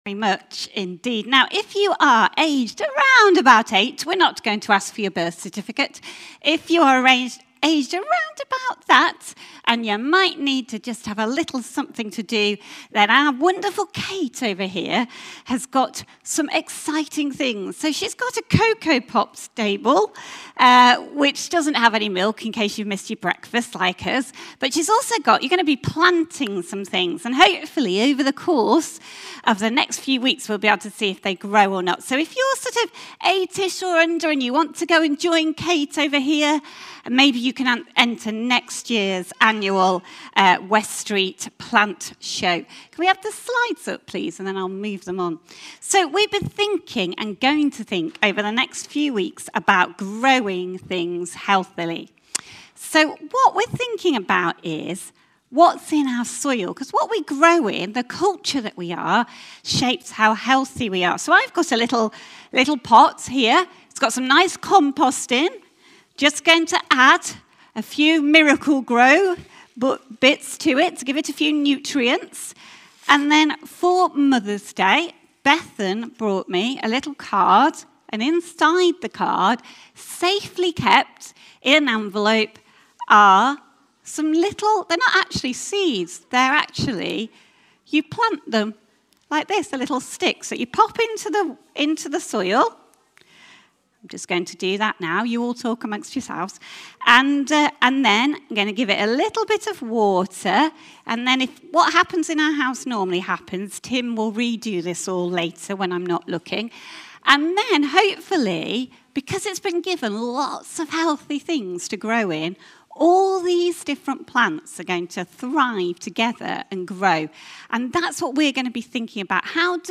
A talk from the series "Encounters with Jesus."